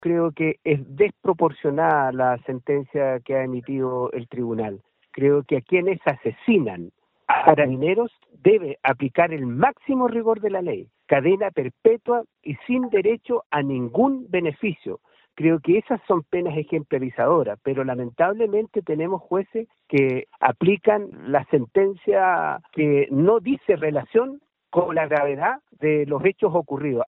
Uno de ellos, el diputado UDI Sergio Bobadilla, calificó como muy baja la sanción, cuestionando a los jueces por no considerar que las víctimas eran uniformados.